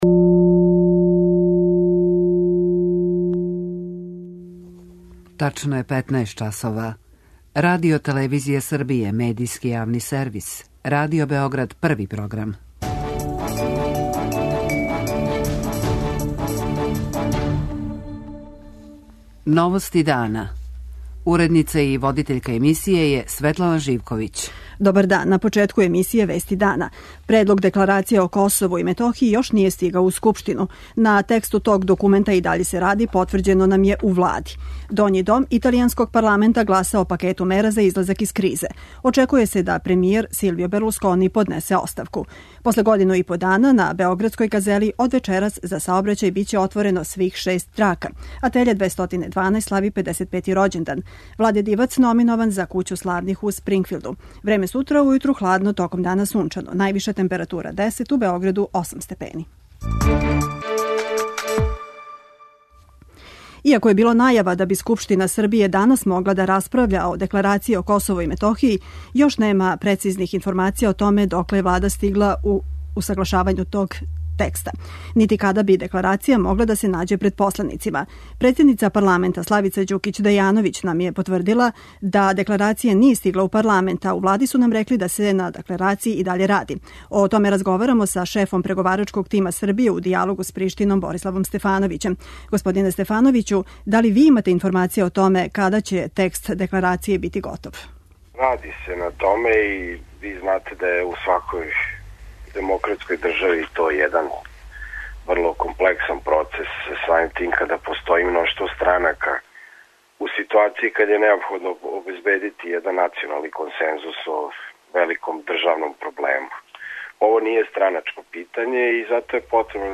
О томе разговарамо са шефом преговарачког тима Србије у дијалогу с Приштином Бориславом Стефановићем.
преузми : 15.49 MB Новости дана Autor: Радио Београд 1 “Новости дана”, централна информативна емисија Првог програма Радио Београда емитује се од јесени 1958. године.